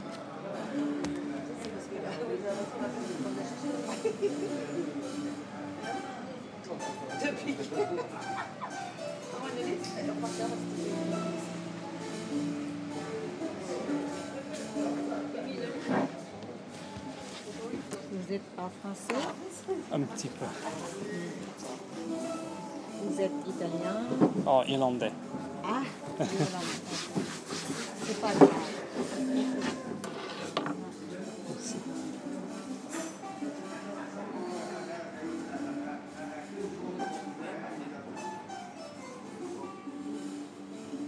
Friday evening in a Saly hotel
Thumb piano, chatter, and I hope I get a dinner :-)